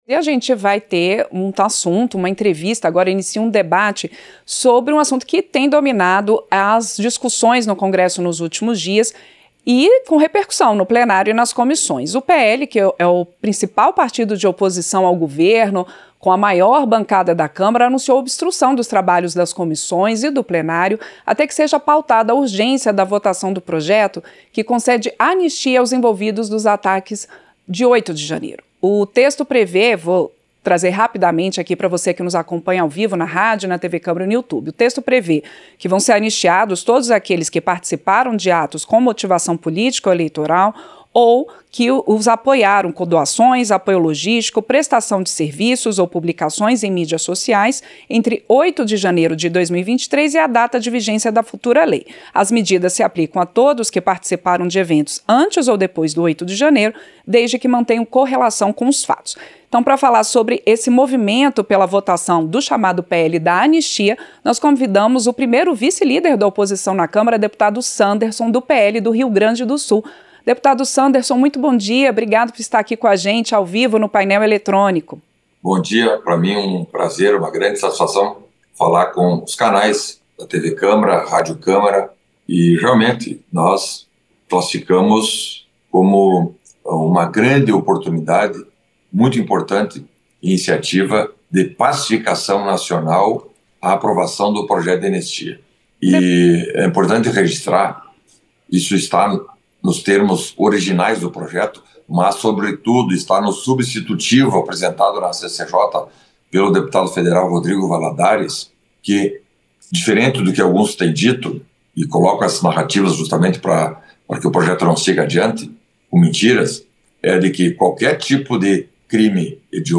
Entrevista - Dep. Sanderson (PL-RS)